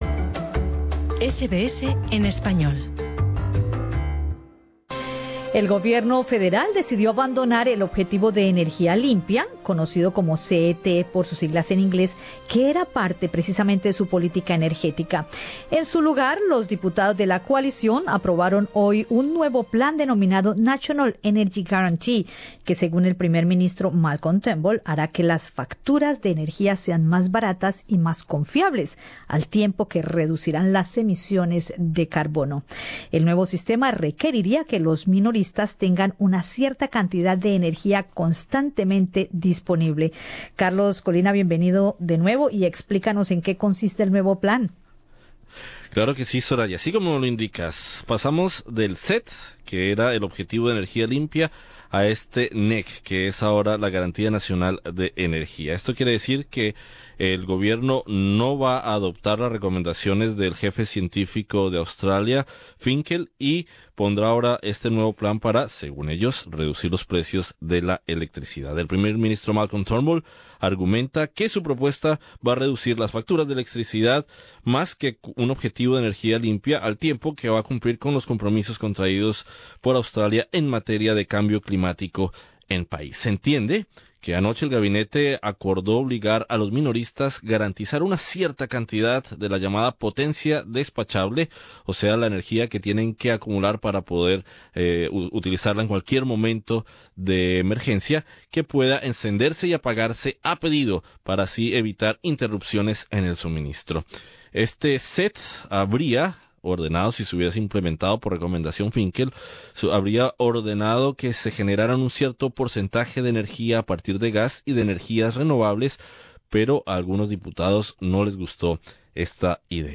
Entrevistamos al investigador y experto en energías renovables y alternativas de la Universidad Monash